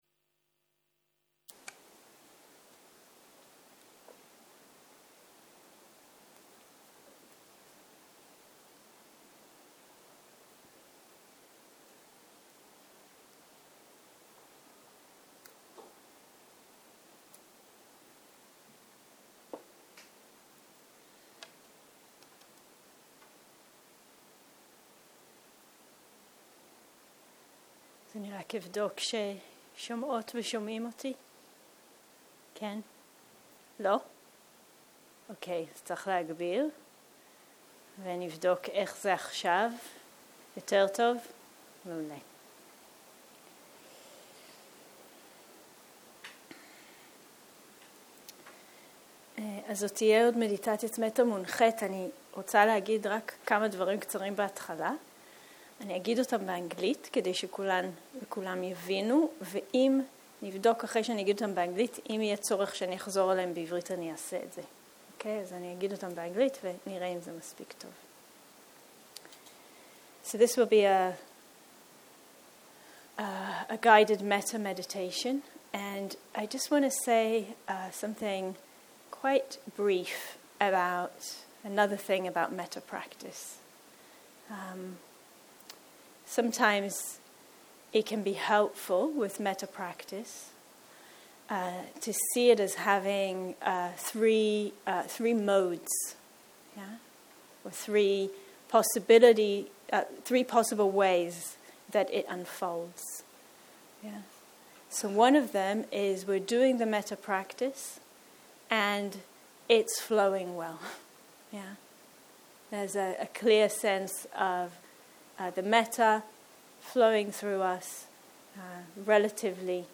צהרים - מדיטציה מונחית - תרגול הכרת תודה ומטא
סוג ההקלטה: מדיטציה מונחית